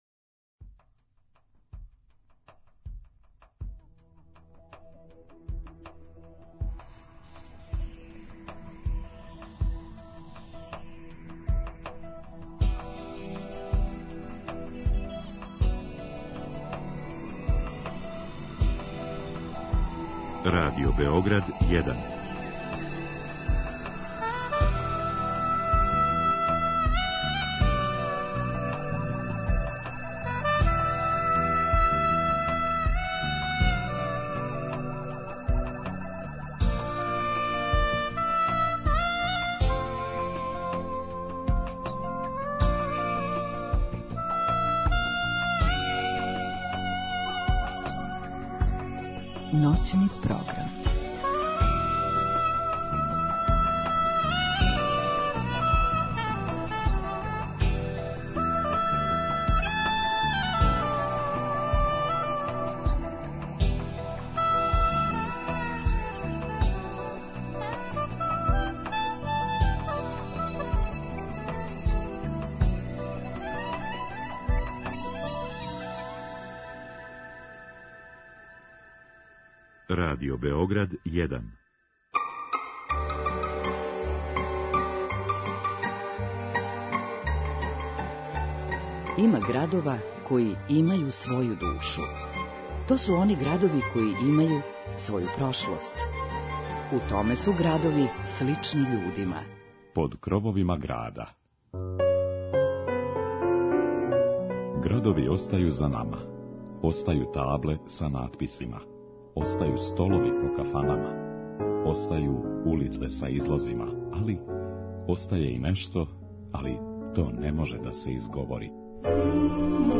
У јулу и августу, путоваћемо музиком по свим крајевима Србије. Да ли су песме које ћете слушати из источне, западне или јужне Србије, Ниша, Чачка или Ужица, сигурно ћете сами препознати.